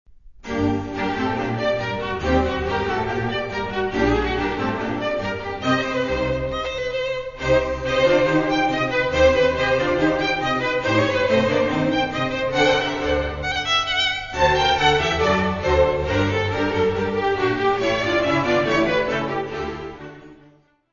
: stereo; 12 cm + folheto
Music Category/Genre:  Classical Music
(Allegro).